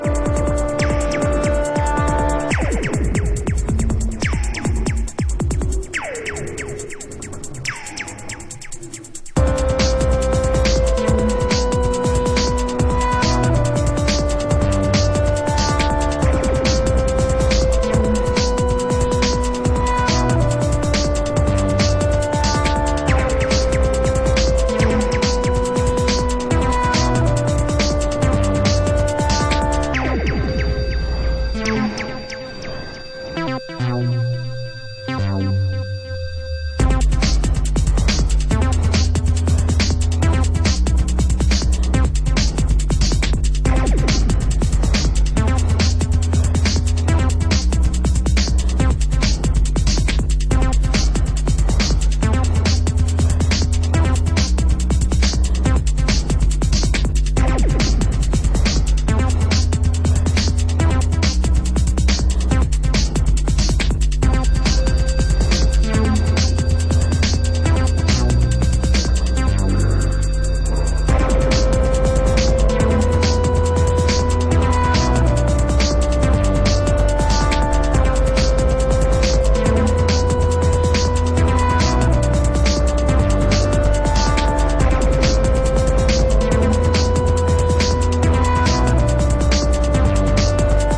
electro / breaks
Electronix